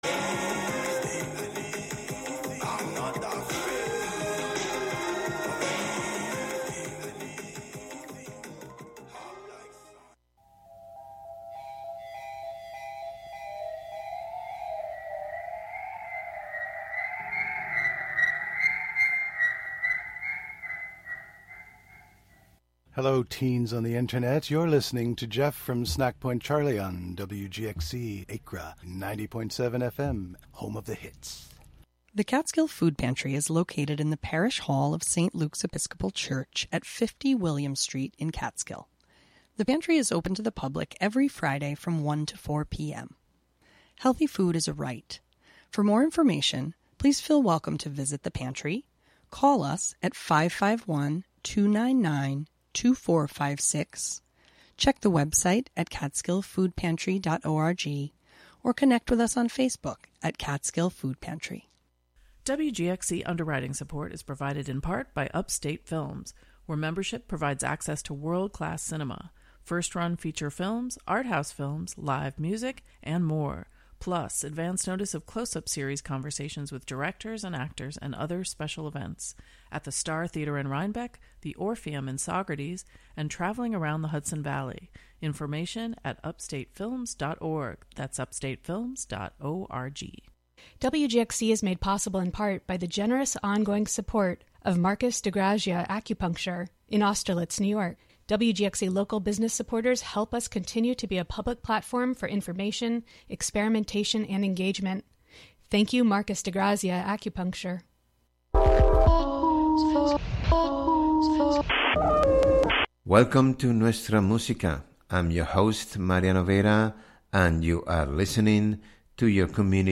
We are presenting Tangos and Milongas, the quasi-mystical melodies of Argentina, that went from modest beginnigs to universally admired style. Tangos from the 1920 and 30 up to current modern Milongas.
Enjoy the melodic and sometimes melancholic music of Buenos Aires. “Nuestra Música,” “Our Music,” presents the rhythms and folklore of Latin America, Spain, Portugal and Cape Verde.